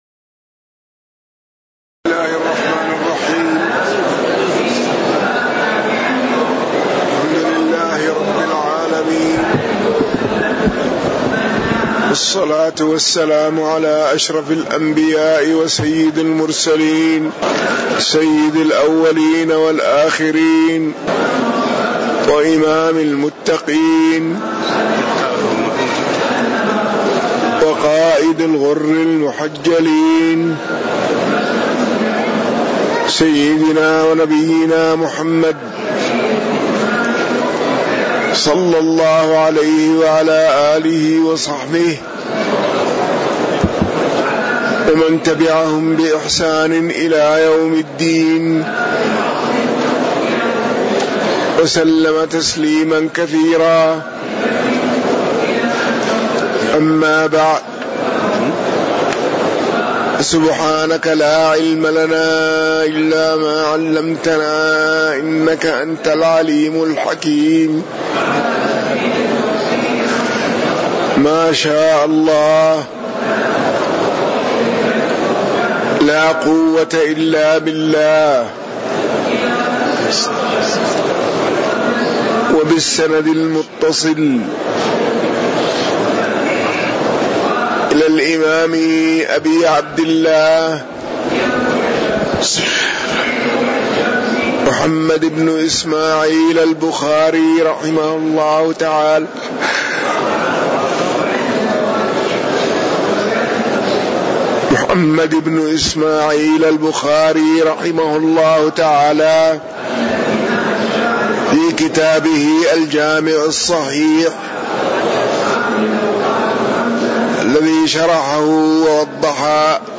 تاريخ النشر ٢٩ ربيع الثاني ١٤٣٩ هـ المكان: المسجد النبوي الشيخ